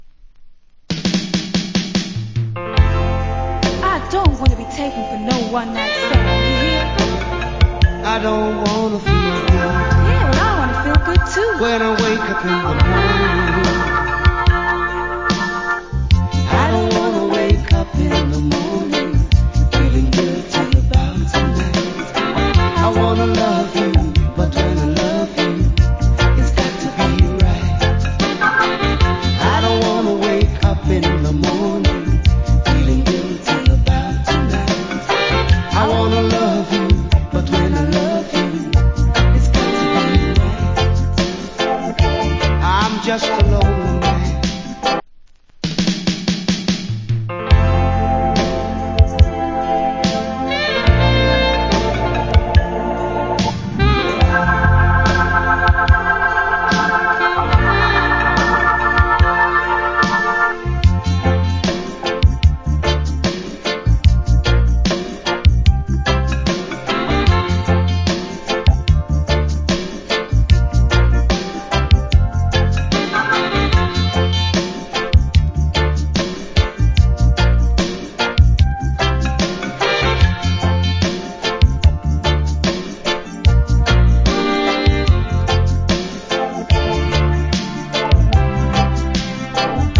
80's Great Duet UK Lovers Rock Vocal.